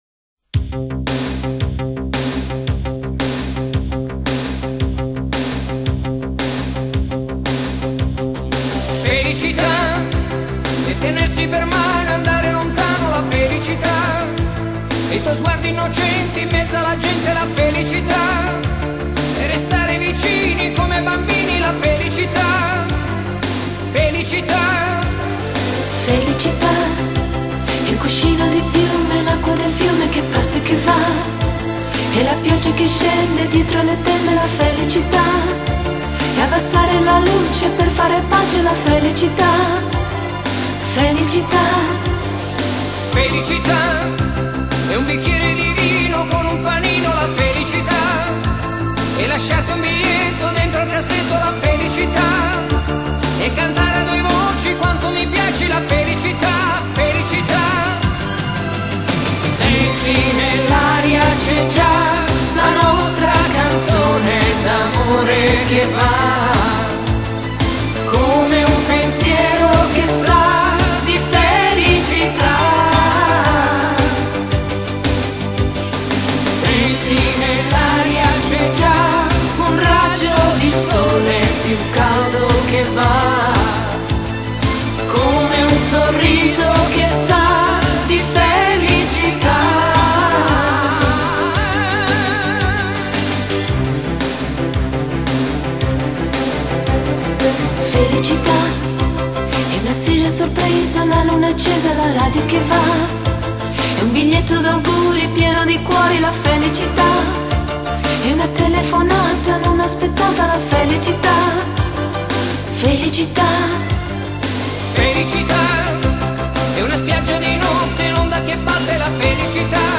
欧美浪漫经典
1984年发表的浪漫歌曲